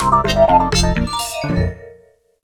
applied fade-out to last two seconds
Fair use music sample